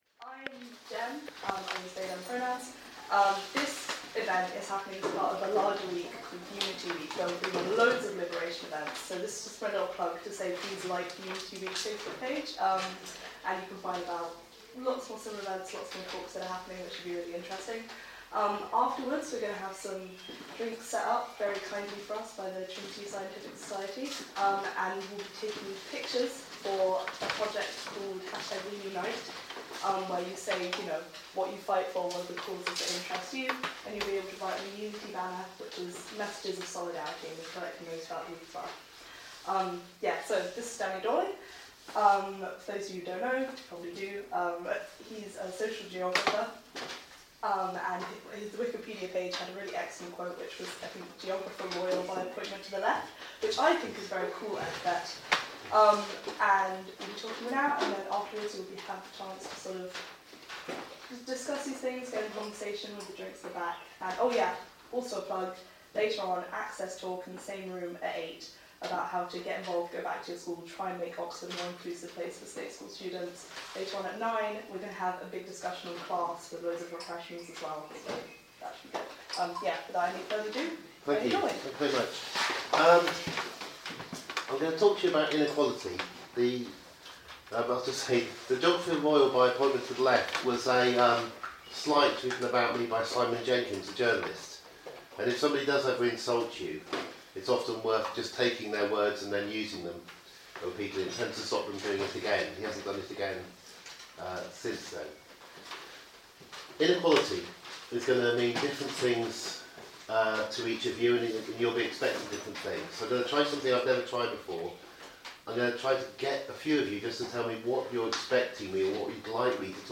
Class of 2015, joint Junior Common Rooms of Trinity, Corpus Christi, St Anne's, Pembroke and Exeter colleges; Trinity College, Oxford West, May 20th 2015